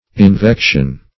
Search Result for " invection" : The Collaborative International Dictionary of English v.0.48: Invection \In*vec"tion\, n. [L. invectio.